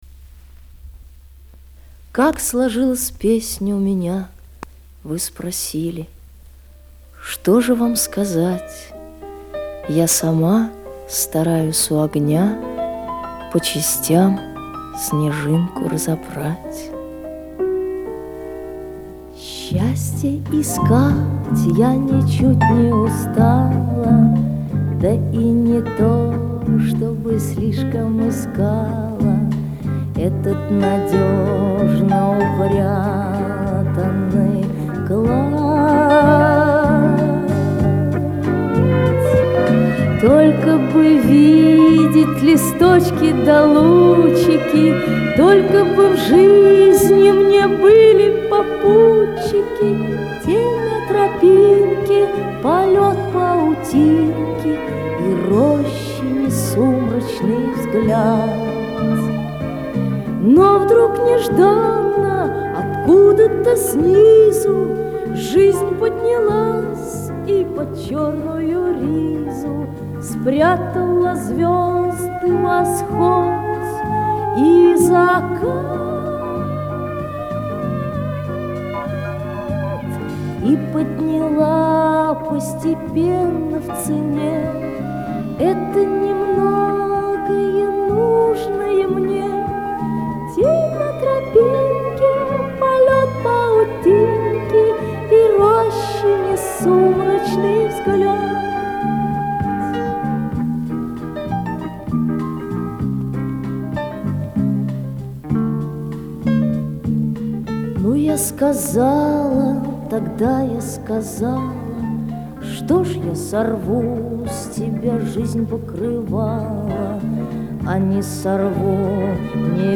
здесь эта песня довольно чисто звучит (2-ая по счёту)